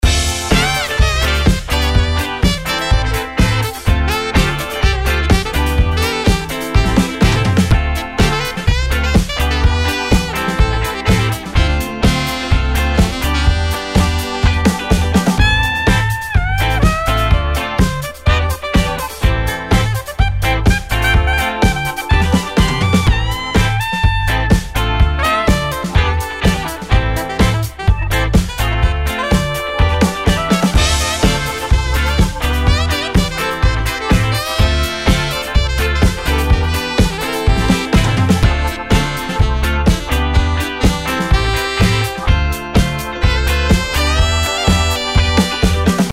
4 Bass Loops: Lay down the groove with punchy, rhythmic basslines that are the heartbeat of any funk track.
20 Drum Beats: Drive the rhythm with tight, syncopated drum beats that keep the funk flowing and the feet moving.
27 Flute Loops: Elevate your sound with smooth, melodic flute lines that bring a touch of sophistication and soul.
27 Guitar Loops: From choppy rhythms to smooth licks, these guitar loops deliver the essential funk guitar vibes.
11 Keys Loops: Infuse your tracks with rich, harmonic textures using our keys loops, perfect for adding depth and warmth.
10 Organ Loops: Add that classic, soulful touch with expressive organ loops that enhance the funk atmosphere.
62 Sax Loops: Complete your tracks with powerful, melodic saxophone lines that bring the ultimate funk flavor.